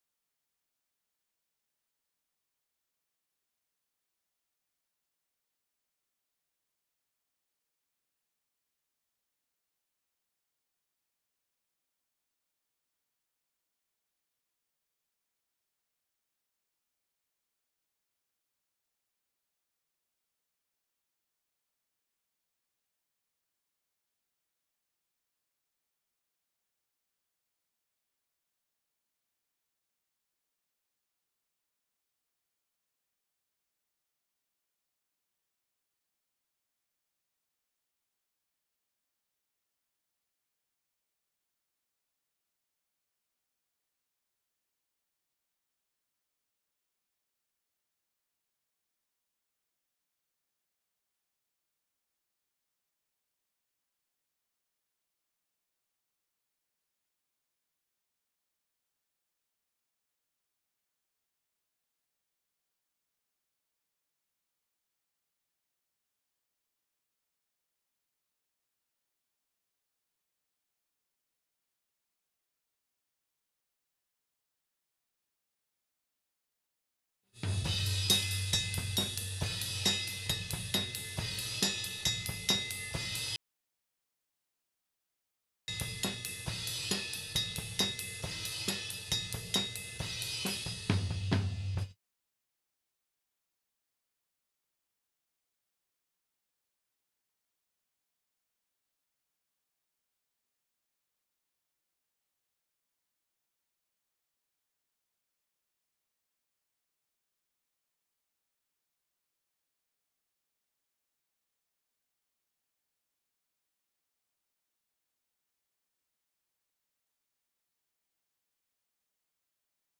Faith Floor Tom Raw.wav